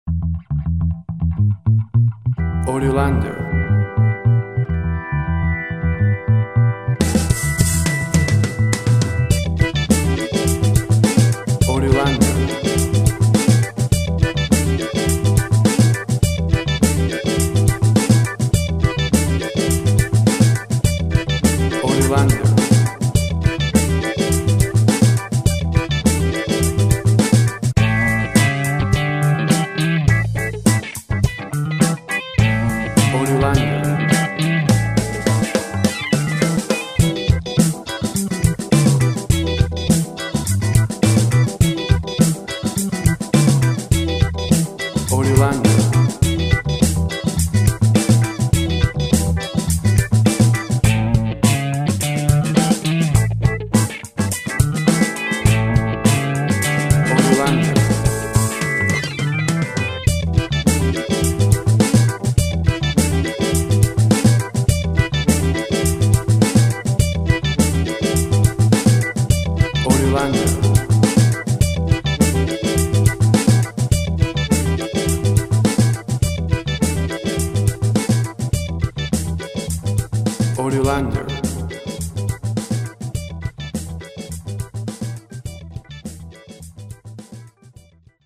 Classic sound of old school Funk.
Tempo (BPM) 110